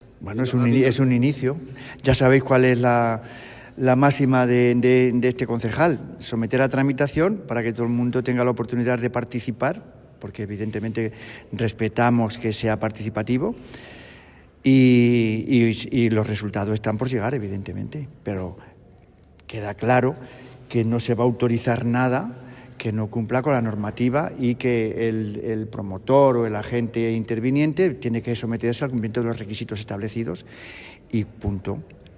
florentino-delgado-comision-urbanismo.mp3